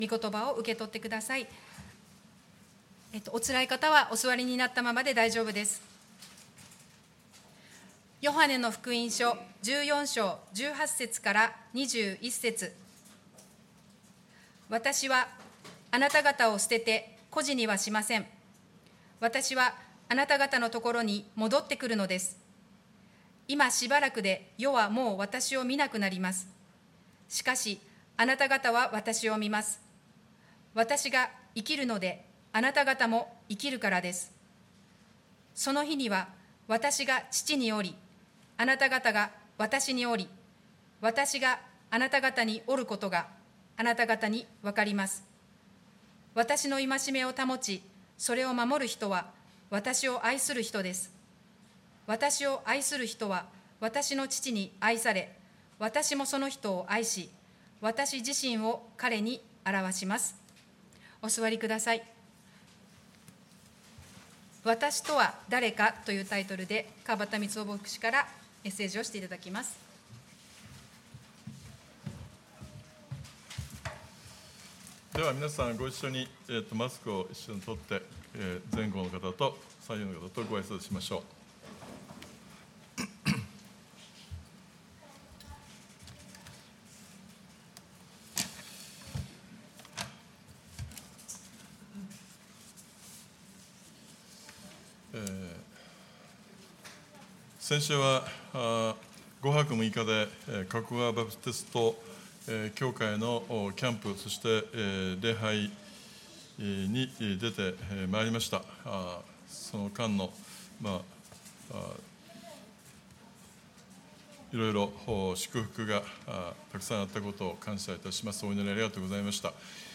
主日礼拝 聖書箇所：ヨハネの福音書 14章 18~21節